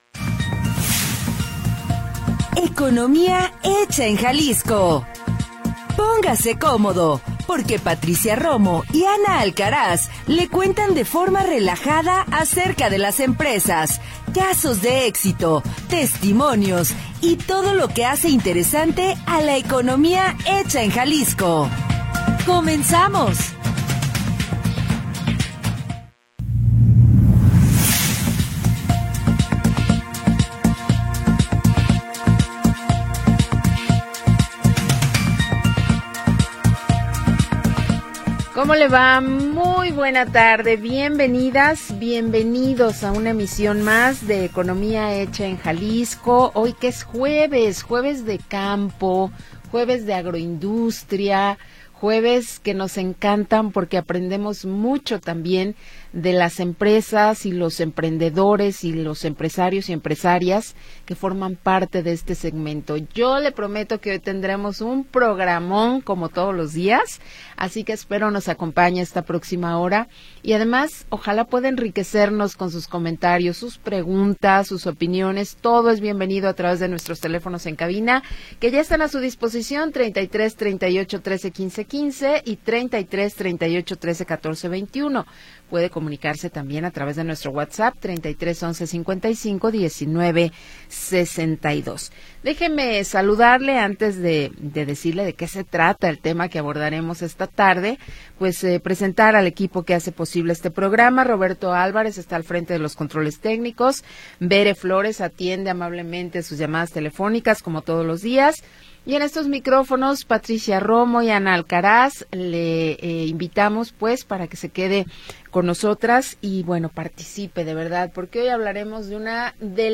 de forma relajada